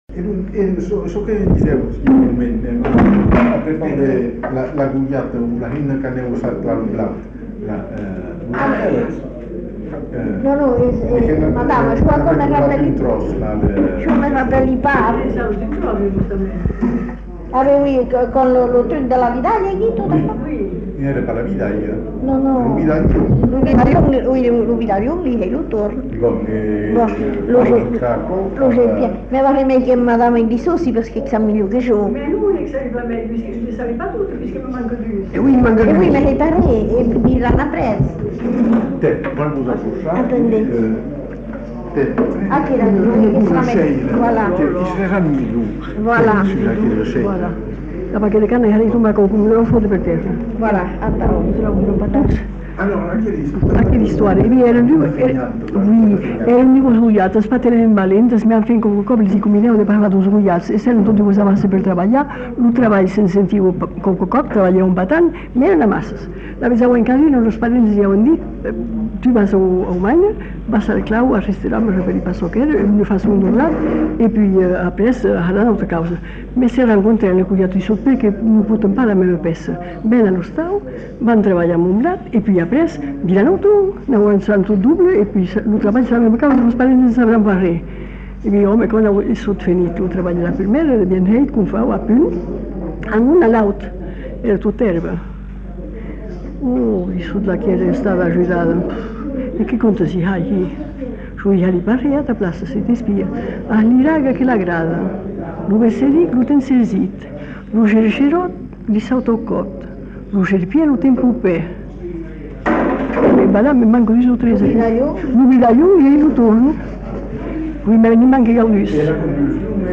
Lieu : Bazas
Genre : conte-légende-récit
Effectif : 1
Type de voix : voix de femme
Production du son : récité
Ecouter-voir : archives sonores en ligne